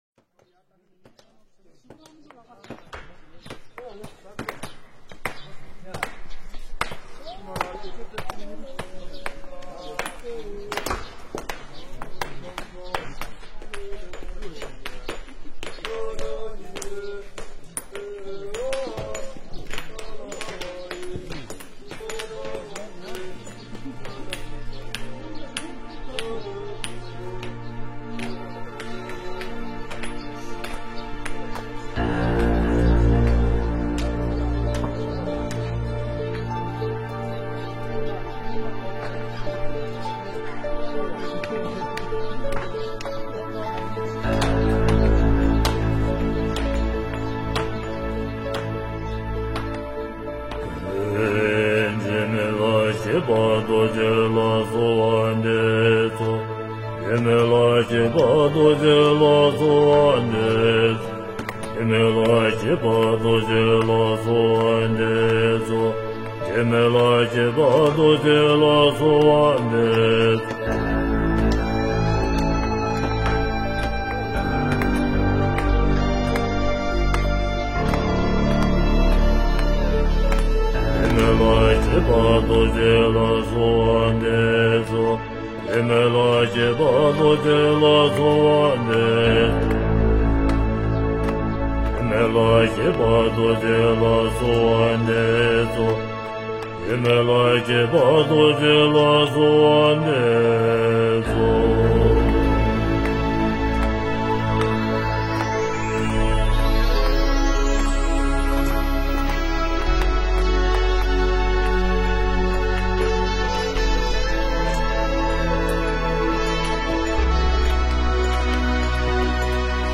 佛音 诵经 佛教音乐 返回列表 上一篇： 六字真言 下一篇： 祥雲吉慶 相关文章 大悲神咒 大悲神咒--梵呗精选...